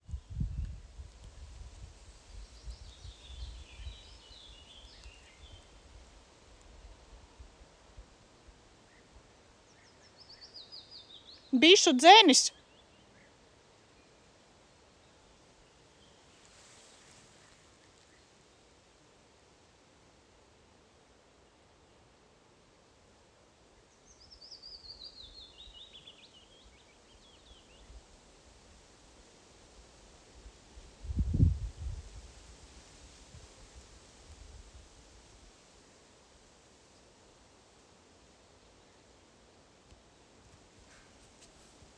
European Bee-eater, Merops apiaster
StatusVoice, calls heard
NotesDzirdēta burboļojoša balss tālumā. Ierakstā var dzirdēt kā fona balsi.